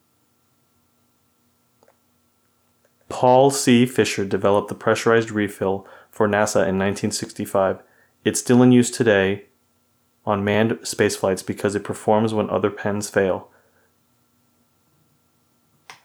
I ran it through AudioBook Mastering 4.
Followed by stiffer than normal Noise Reduction (9, 6, 6).